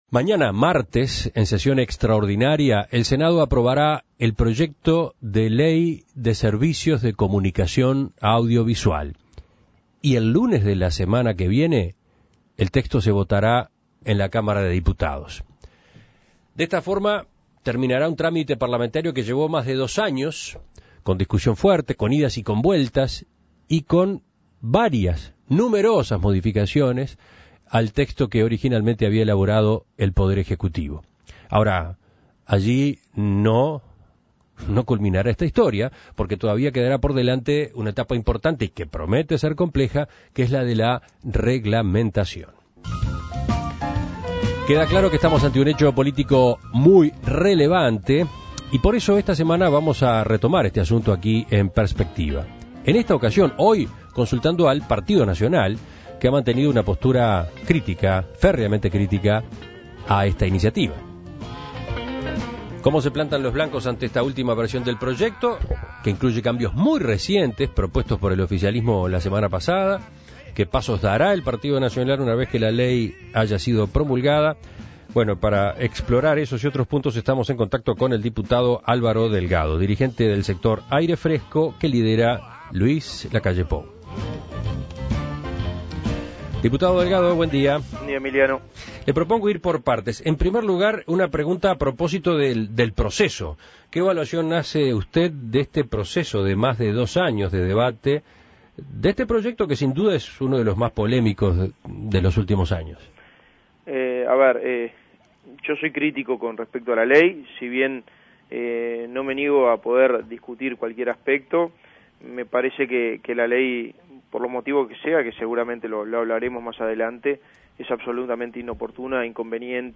Sin embargo, tiempo de después, se puso a disposición del Parlamento un proyecto de Ley de Servicios de Comunicación Audiovisual que ha sido objeto de varias polémicas y modificaciones. Este martes se aprobará en el Senado con los votos del oficialismo, y para conocer el punto de vista de la oposición, En Perspectiva entrevistó al diputado nacionalista Álvaro Delgado.